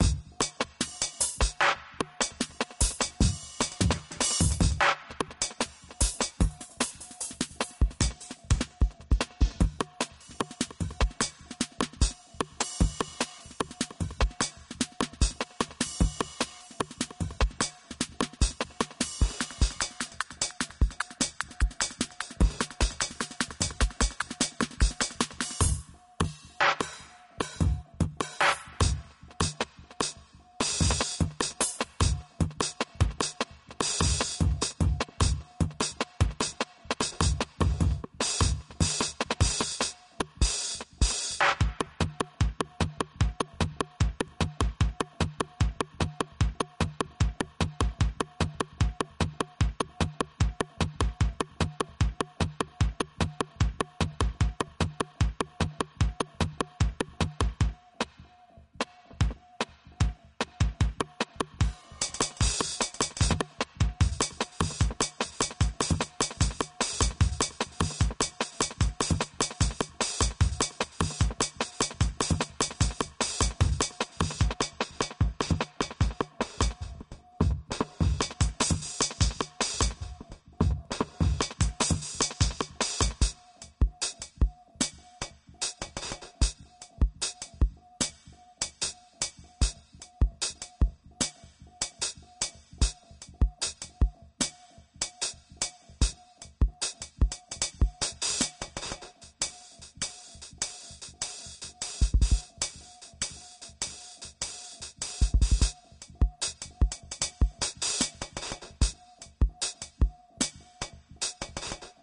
Descarga de Sonidos mp3 Gratis: bateria 5.
ruido-sordo_1.mp3